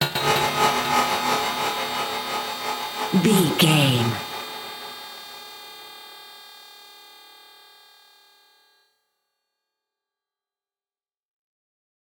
In-crescendo
Aeolian/Minor
tension
ominous
suspense
haunting
eerie
synth
ambience
pads
eletronic